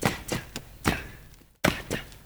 FOOTWORK  -L.wav